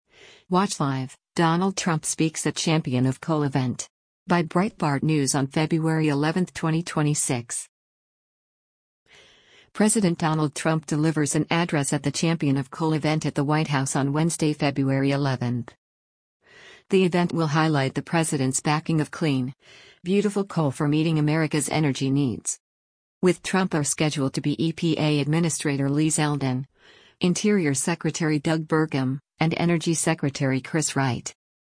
President Donald Trump delivers an address at the “Champion of Coal” event at the White House on Wednesday, February 11.